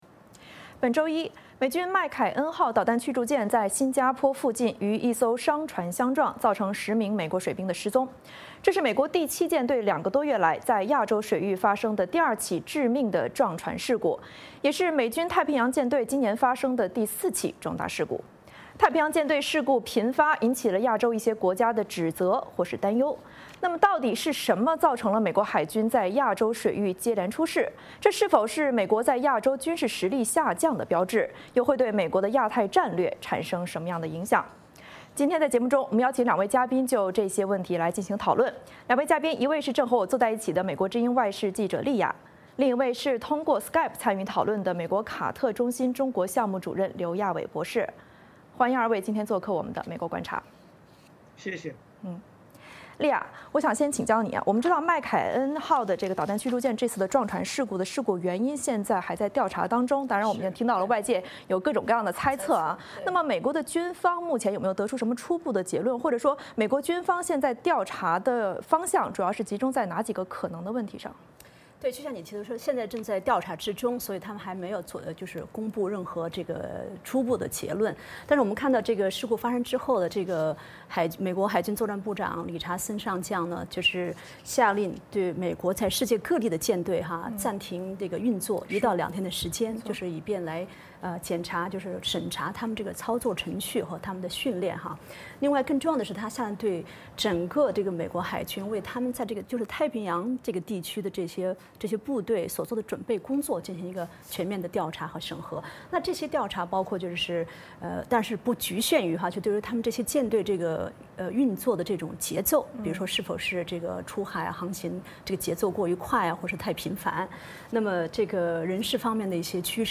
这是否是美国在亚洲军事实力下降的标志，又会对美国亚太战略产生什么样的影响？我们邀请了两位嘉宾就这些问题进行讨论。